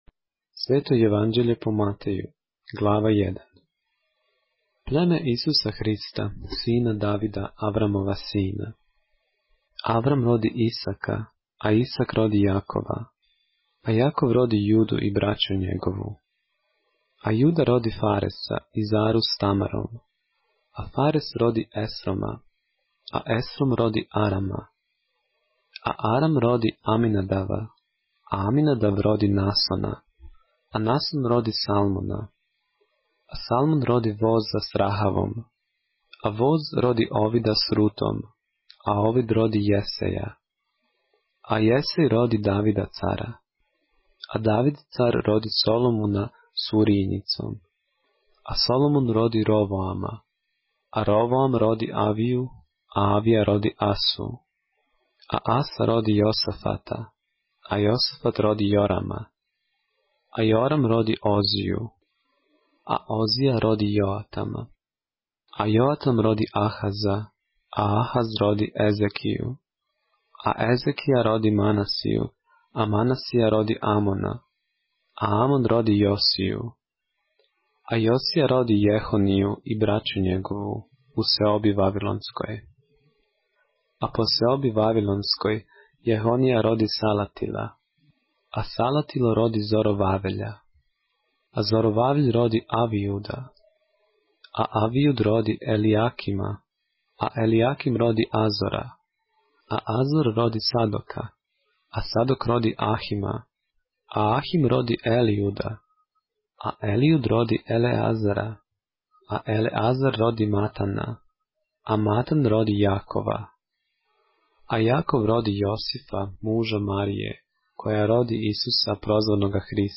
поглавље српске Библије - са аудио нарације - Matthew, chapter 1 of the Holy Bible in the Serbian language